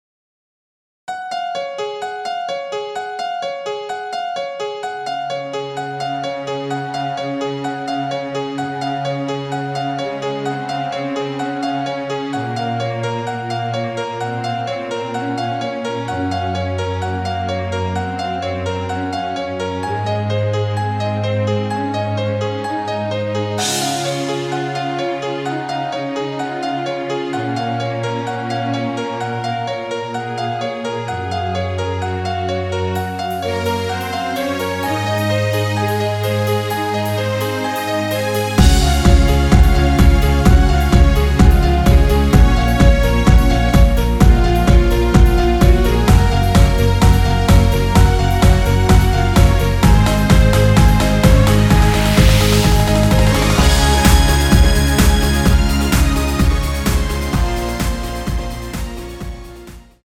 엔딩이 페이드 아웃이라 엔딩을 만들어 놓았습니다.(멜로디 MR 미리듣기 확인)
Db
앞부분30초, 뒷부분30초씩 편집해서 올려 드리고 있습니다.
중간에 음이 끈어지고 다시 나오는 이유는